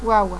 (popular canario)